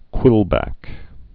(kwĭlbăk)